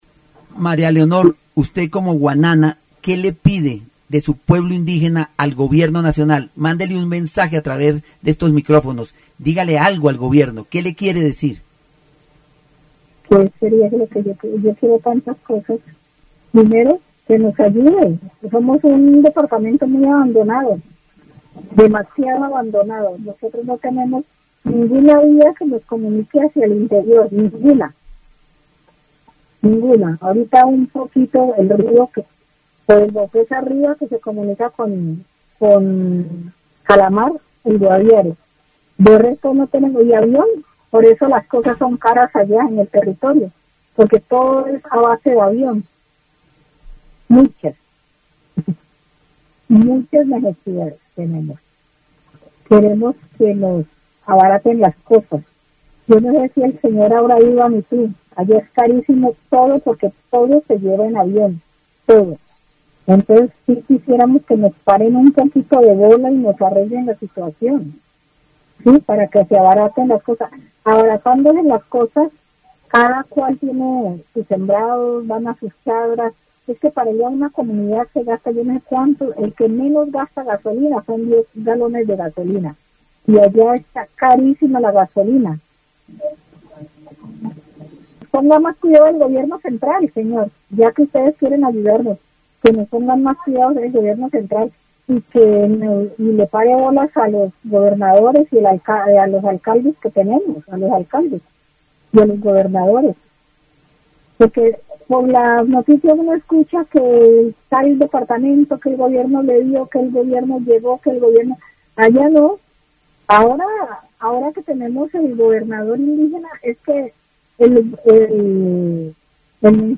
Mujeres – Entrevistas
Programas de radio